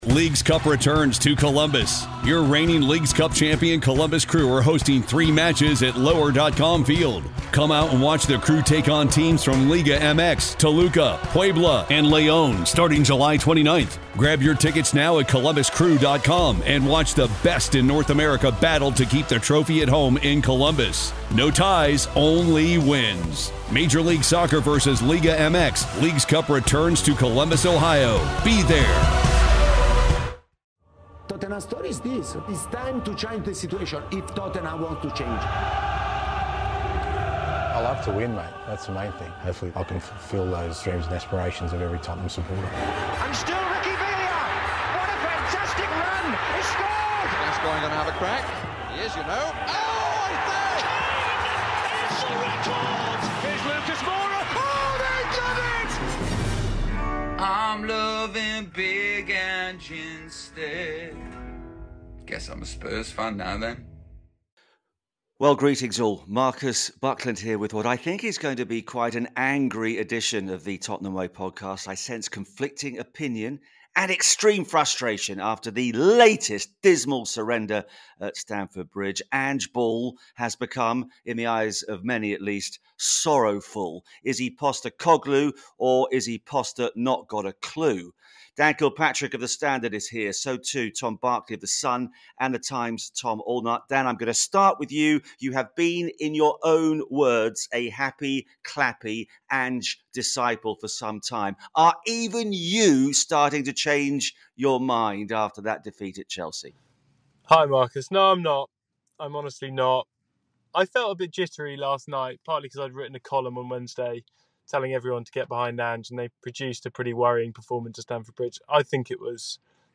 recording roadside